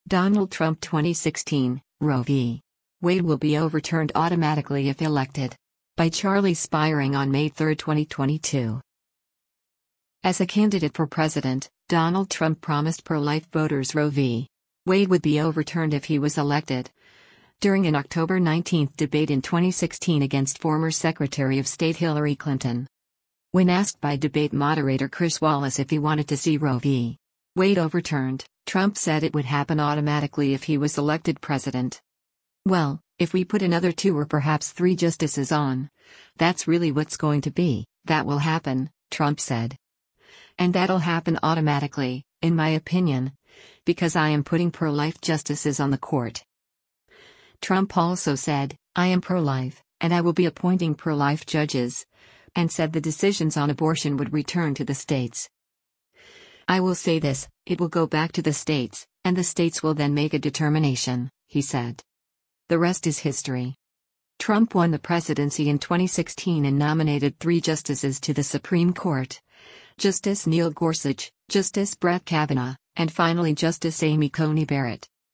As a candidate for president, Donald Trump promised pro-life voters Roe v. Wade would be overturned if he was elected, during an October 19 debate in 2016 against former Secretary of State Hillary Clinton.
When asked by debate moderator Chris Wallace if he wanted to see Roe v. Wade overturned, Trump said it would happen automatically if he was elected president.